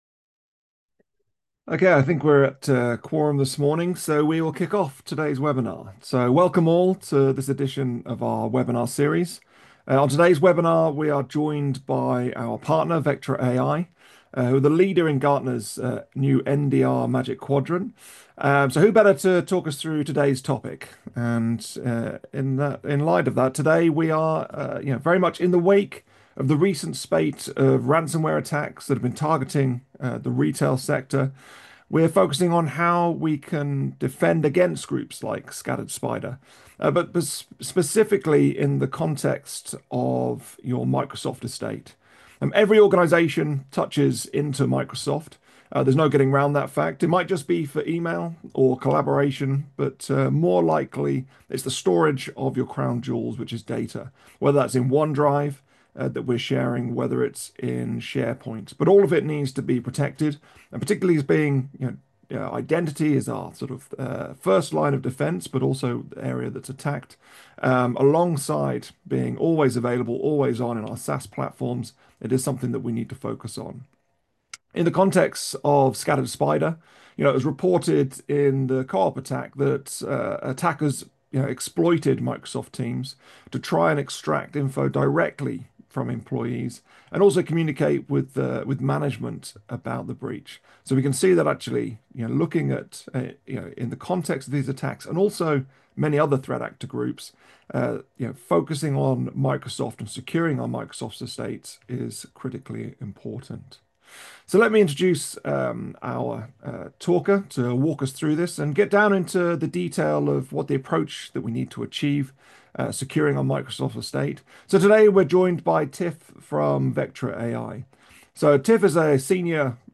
Integrity360 | Webinar | Advance your Microsoft security on-demand